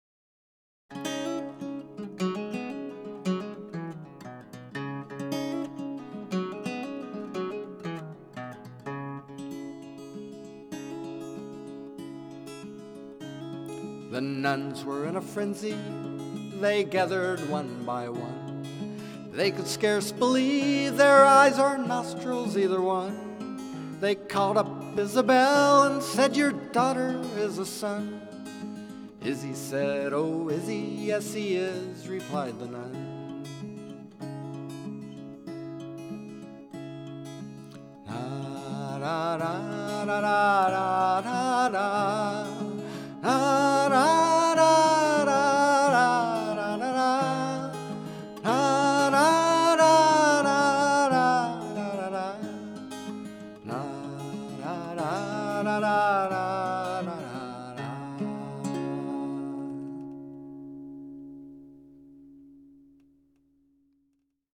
"A Flask of Spanish Perfume" (This is a short excerpt from a nearly endless, and pointless, British folk ballad parody.)
Forward [mp3] — Original